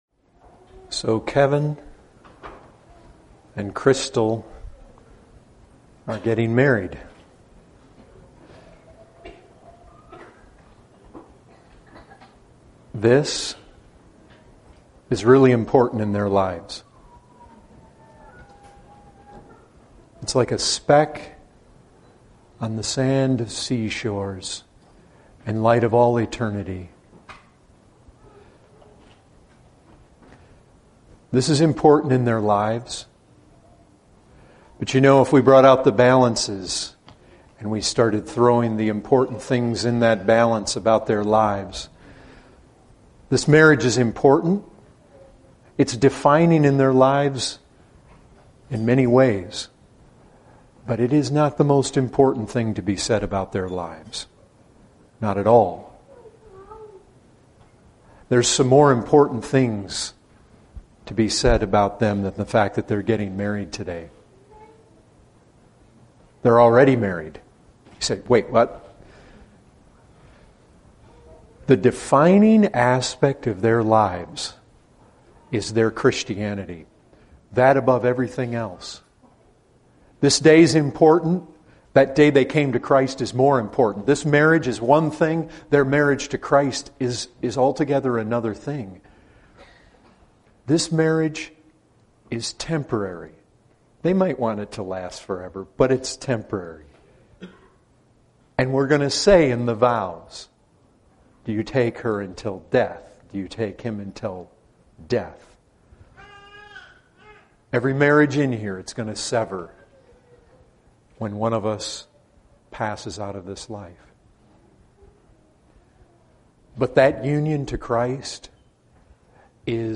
14:49 | Wedding | Marriage is indeed important and defining in the believer's life, but it is not the most important thing to be said of the Christian's life.